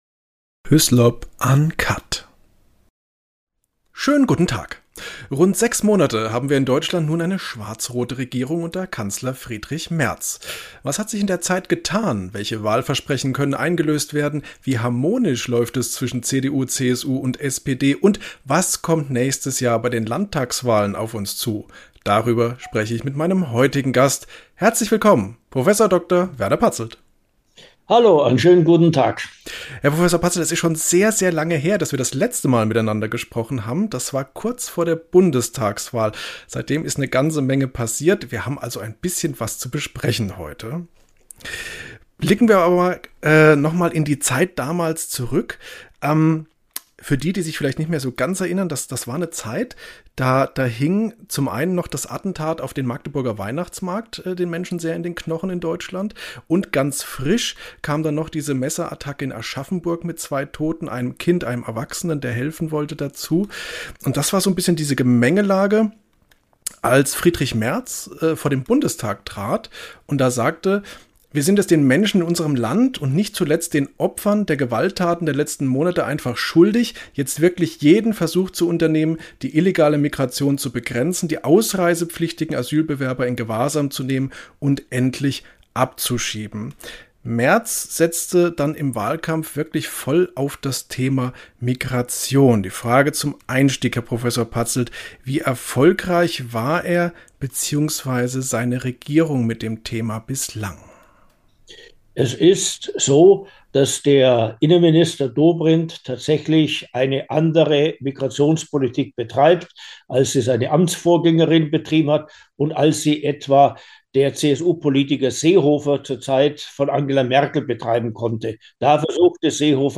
Über all das habe ich mit dem Politikwissenschaftler Prof. Dr. Werner J. Patzelt gesprochen.